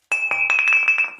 BottleDropping.ogg